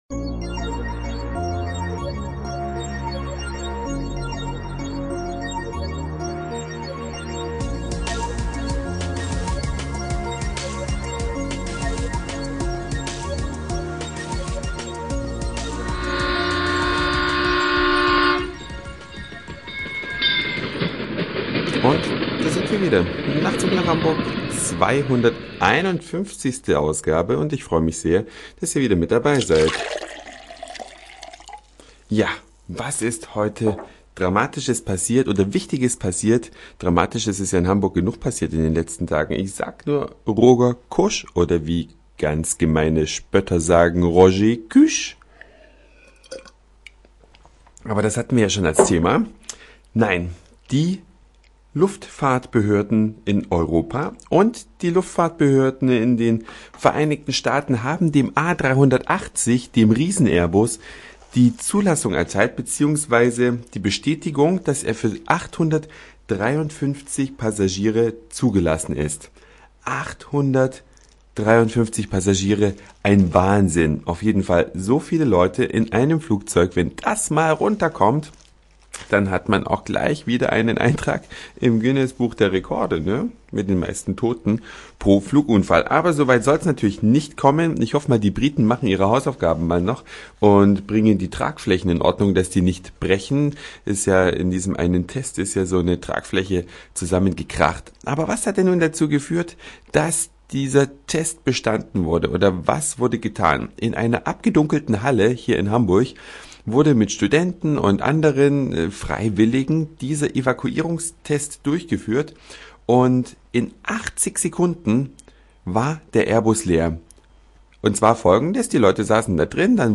Eine Reise durch die Vielfalt aus Satire, Informationen, Soundseeing und Audioblog.
Ein akustischer Hinweis auf den letzten Sommer.